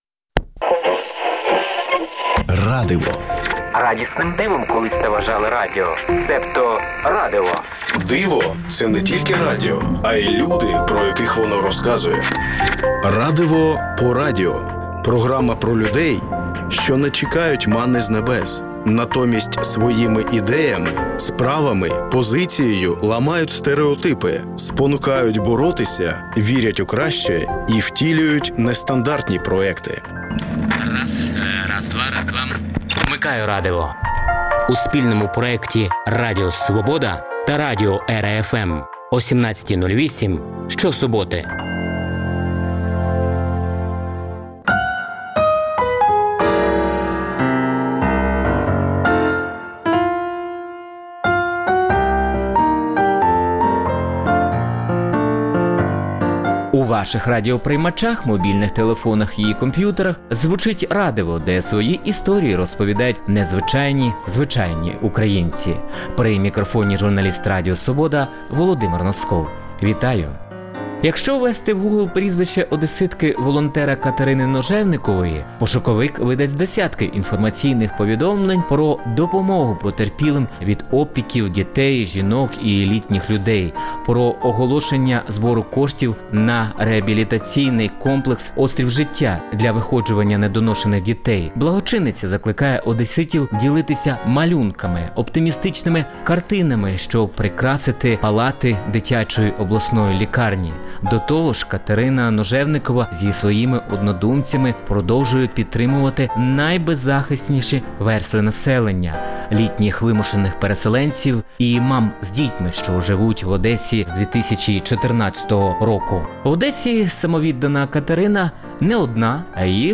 «Радиво» по радіо – це спільний проект Радіо Свобода та радіо «ЕРА-Fm» що присвячується людям, які не чекають манни з небес, натомість своїми ідеями, справами, позицією ламають стериотипи, спонукають боротися, вірять у краще і втілюють нестандартні проекти. Вмикайте «Радиво» що-суботи О 17.08. В передачі звучатимуть інтерв’ю портретні та радіо замальовки про волонтерів, військових, вчителів, медиків, громадських активістів, переселенців, людей з особливими потребами тощо.